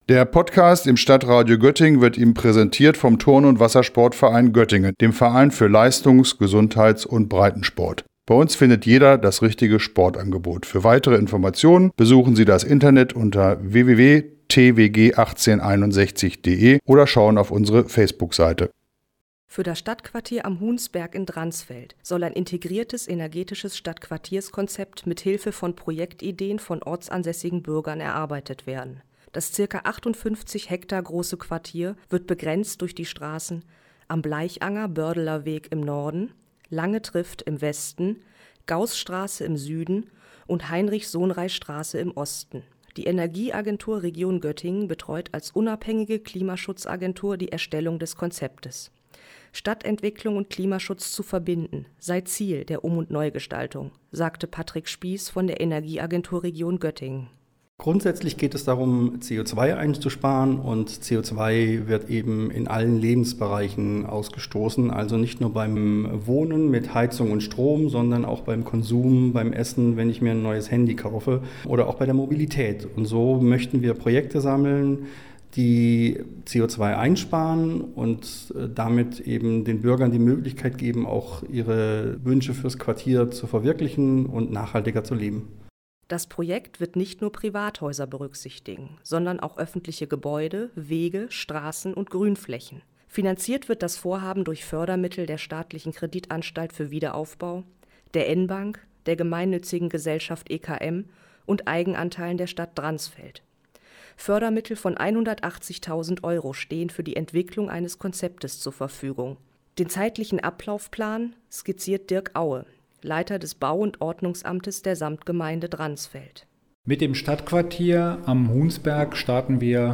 Gemeinsam mit Dransfelder Bürgern, Gewerbetreibenden und Institutionen will die Stadtverwaltung Dransfeld ein Konzept für das Stadtquartier am Huhnsberg entwickeln. Mehr als 60 Interessierte haben die erste Bürgerversammlung für dieses Vorhaben in der Stadthalle in Dransfeld besucht.